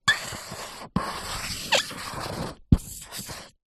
Звуки маркера
скрипучий маркер